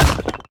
snd_rock.ogg